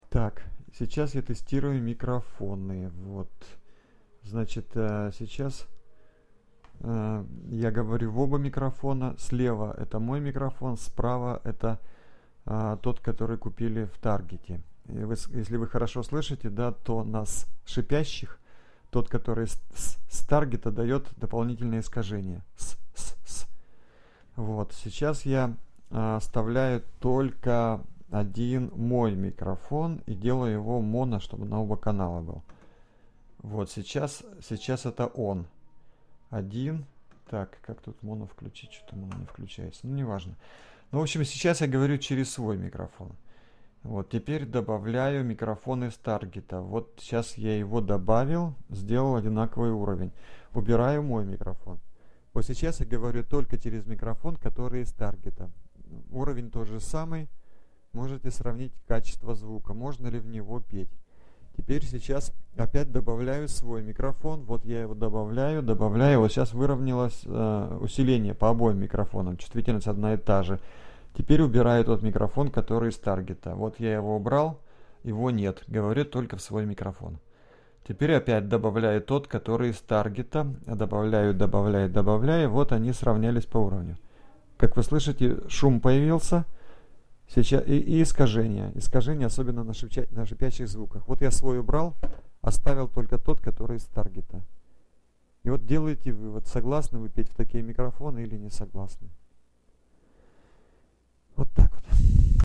There is the test record: the left channel uses my microphones with Chinese copies of M7 capsules, the right one is the one I tested. Though you don't understand Russian you for sure will understand that the right one sounds harsh, it modulates the signal by an envelope of upper frequency sounds, and on transients it has crossover distortions that absent on steady sounds.
mictest.mp3